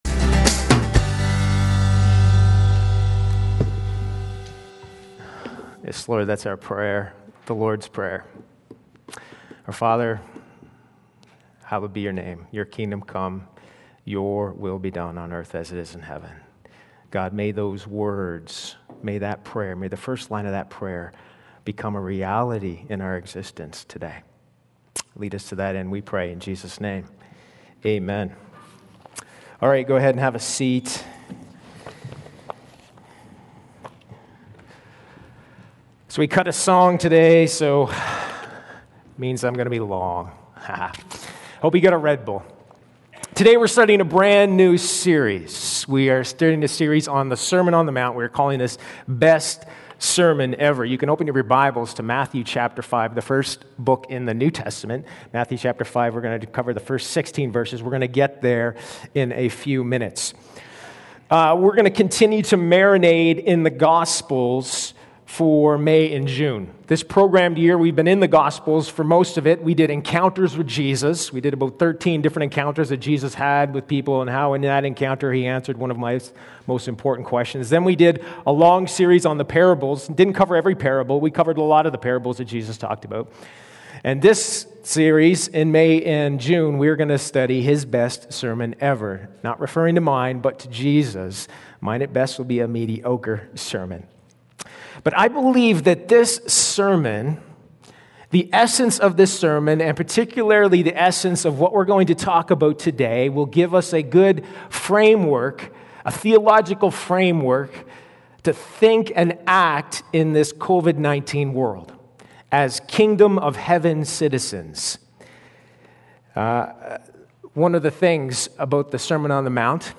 Sermons | Sturgeon Alliance Church
1/ The Sermon on the Mount. The Beatitudes. Mathew 5:1-16